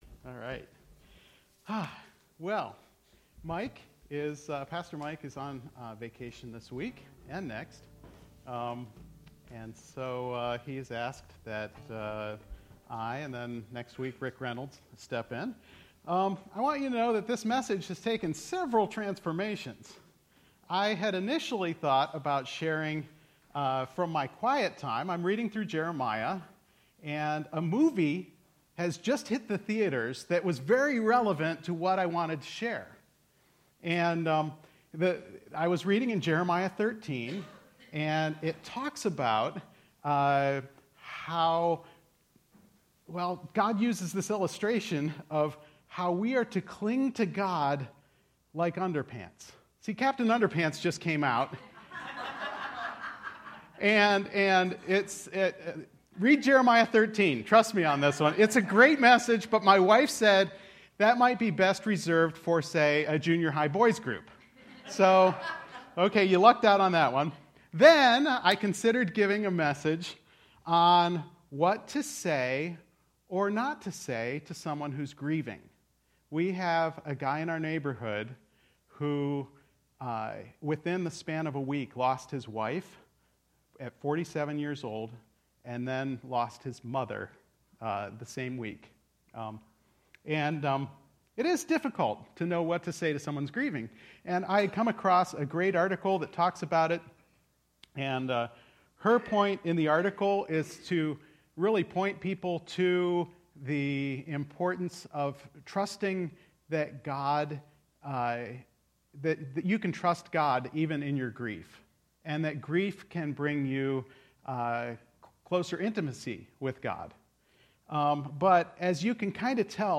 Grace Summit Community Church | Cuyahoga Falls, Ohio